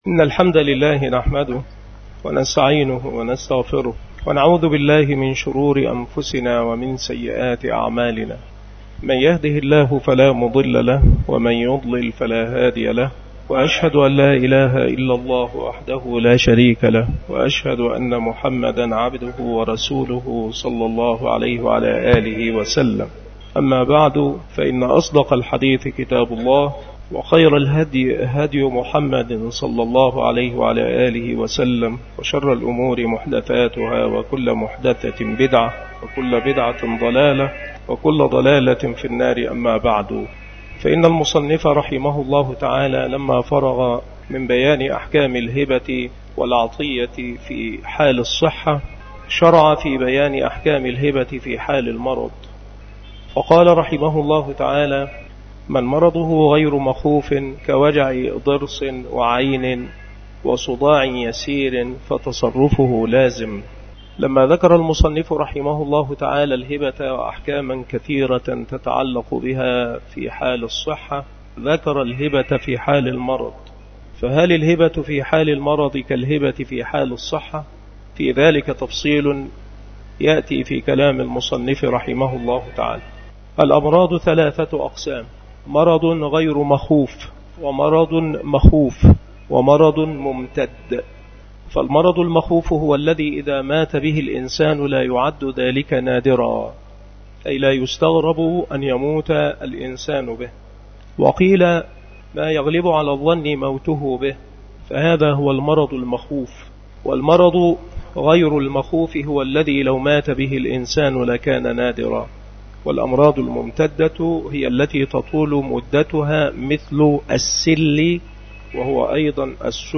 مكان إلقاء هذه المحاضرة بمسجد صلاح الدين بمدينة أشمون - محافظة المنوفية - مصر